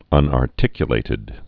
(ŭnär-tĭkyə-lātĭd)